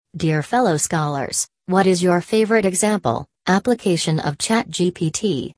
Convert Text To Speech
• It offers natural-sounding voices for text to speech conversion.